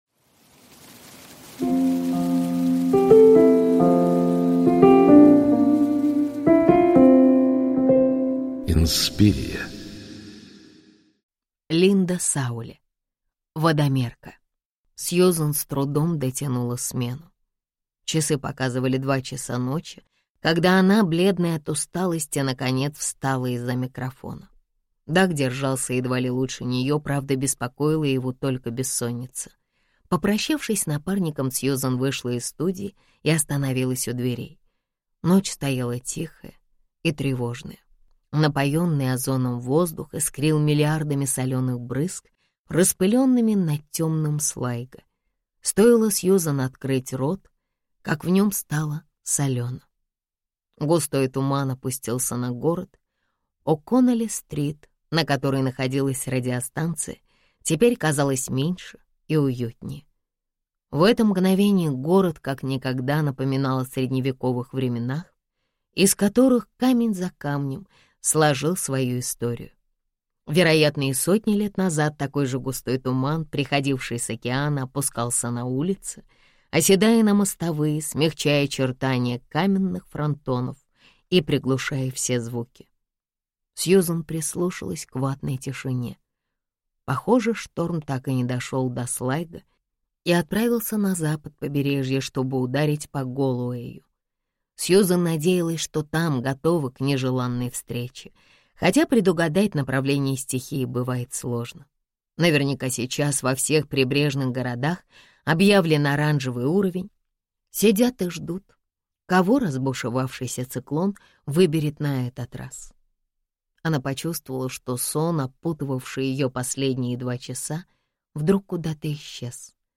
Аудиокнига Водомерка | Библиотека аудиокниг